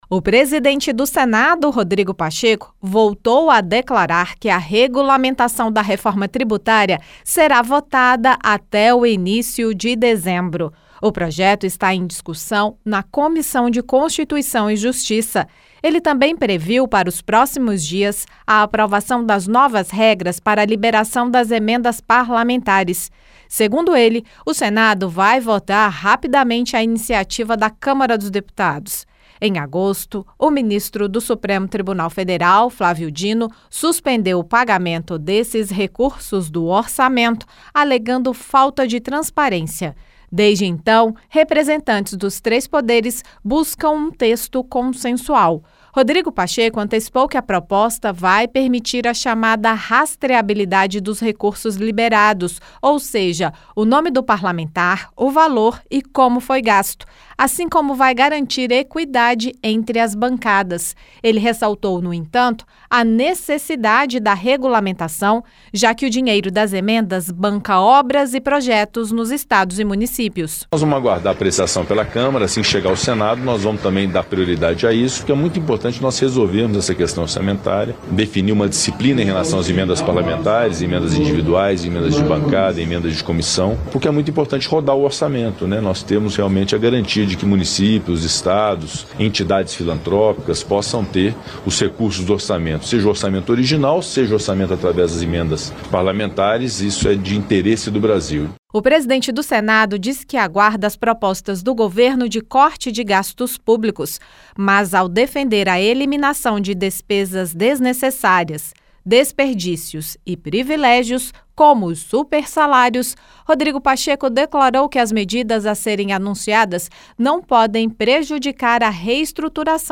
O presidente do Senado, Rodrigo Pacheco, afirmou em entrevista nesta terça-feira (5) que a regulamentação da reforma tributária (PLP 68/2024) deverá ser aprovada até o início de dezembro. Ele também prevê a votação ainda este ano do projeto que cria regras para a liberação das emendas parlamentares e afirma que esse dinheiro bloqueado banca obras importantes nos estados e municípios. Rodrigo Pacheco declarou ainda que as medidas de cortes no Orçamento a serem anunciadas pelo governo federal não podem afetar as reestruturação de carreiras do funcionalismo público.